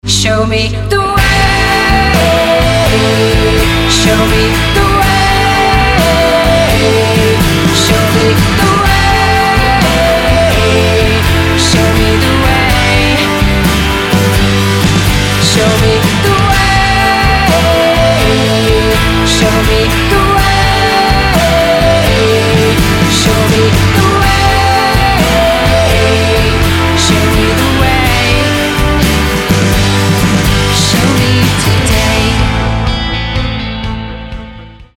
Írska rocková skupina z 90tych rokov